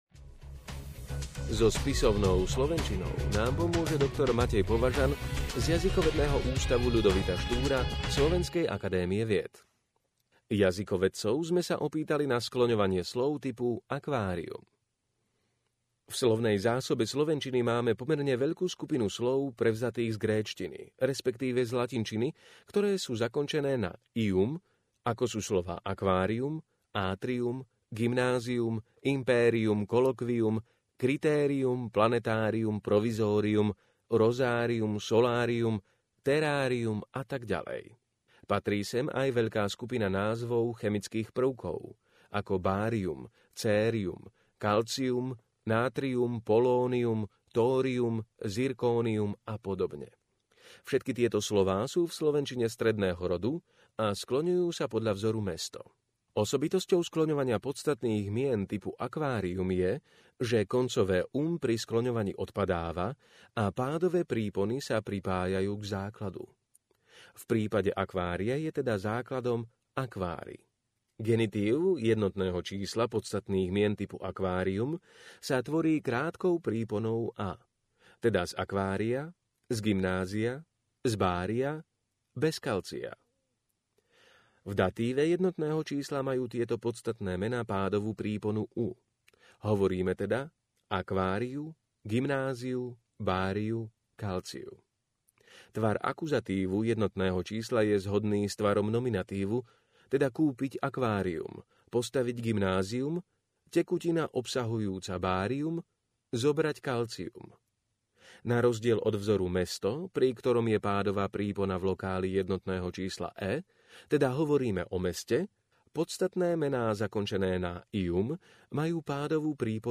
Opäť vás tu čaká obľúbené hodinové čítanie z nového Quarku, aby si mohli vaše oči oddýchnuť.